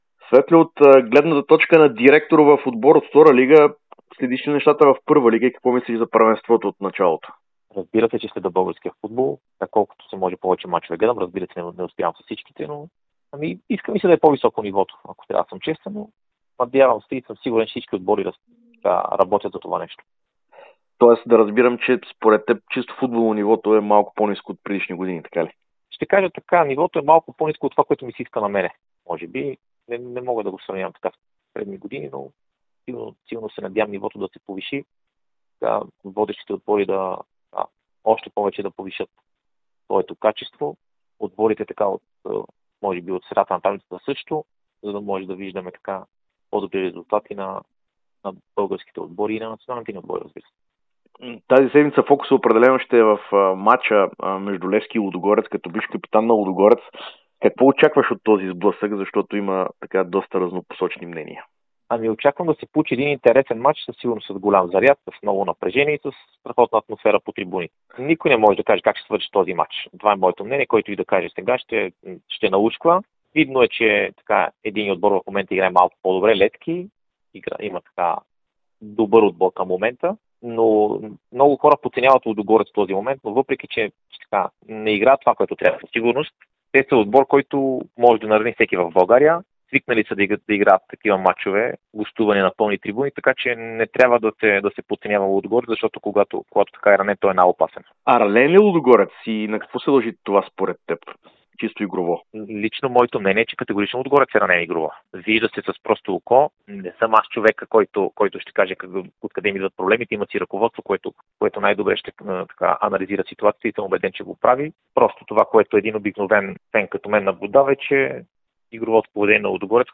Бившият капитан и треньор на Лудогорец Светослав Дяков даде специално интервю за Дарик радио и dsport преди дербито между Левски и шампионите в петък.... (16.09.2025 16:36:50)